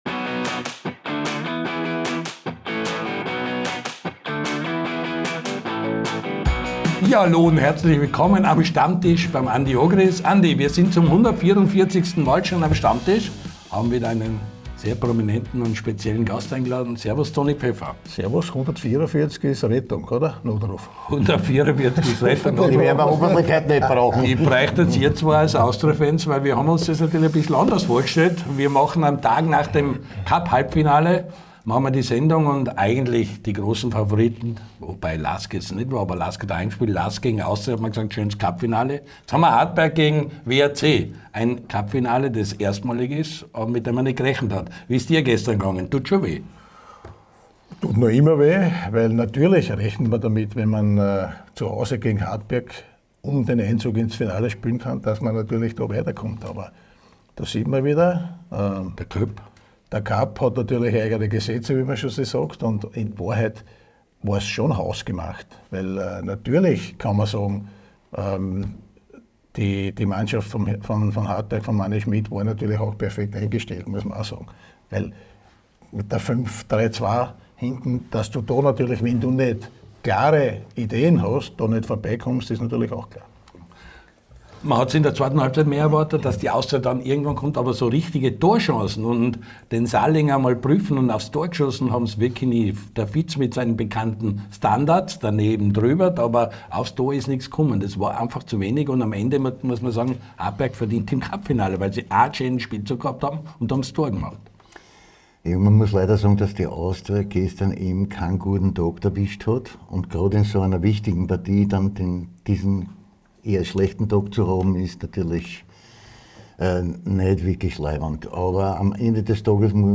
Zwei Austria-Legenden sprechen am Stammtisch über das bittere Cup-Aus der "Veilchen". Nicht zu kurz kommen legendäre Geschichten, viel Schmäh und die Bedeutung einer lebenslangen Freundschaft.